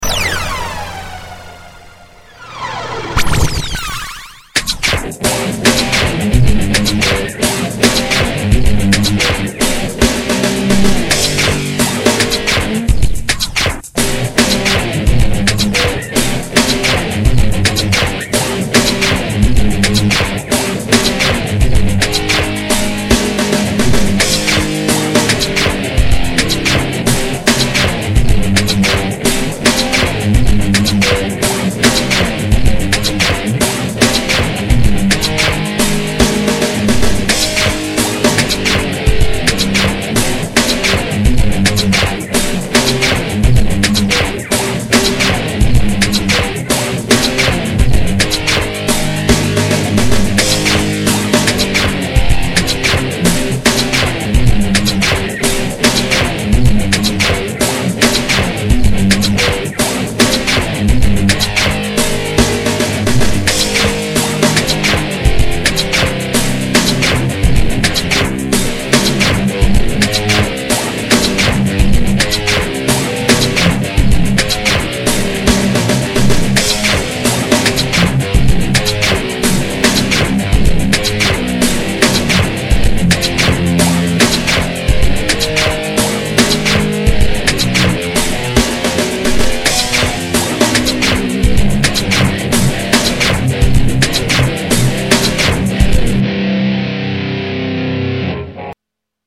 I composed all of these pieces and played all guitars. Drums, keyboards, and some bass guitars I programmed via computer and some bass guitars are my playing an actual bass guitar.
Heavy industrial type tune.  MIDI drums and bass with misc. samples. 2/2000